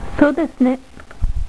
Prononciation: "Soo dess' né."
Le "ne" se prononce comme le mot "nez" en français, et peut être court ou long (ou même très long), à choix, selon l'expressivité que vous voulez donner à votre avis.